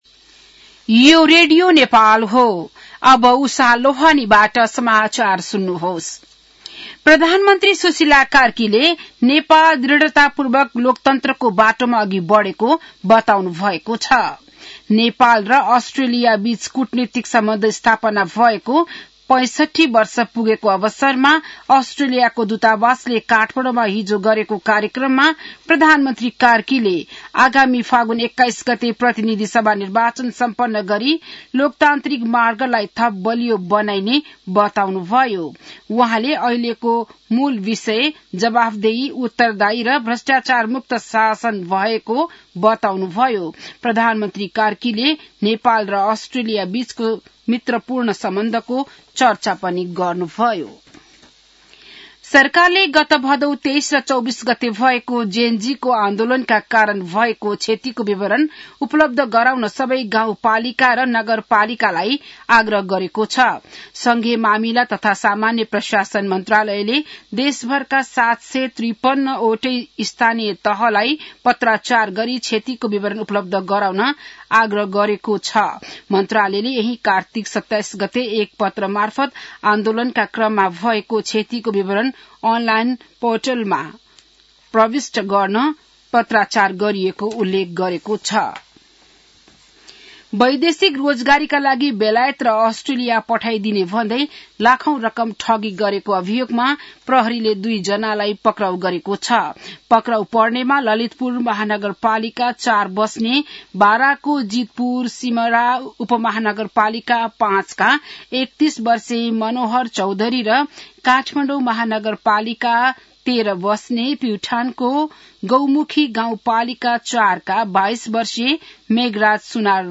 बिहान १० बजेको नेपाली समाचार : ३० कार्तिक , २०८२